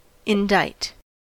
Ääntäminen
IPA : /ˌɪnˈdaɪt/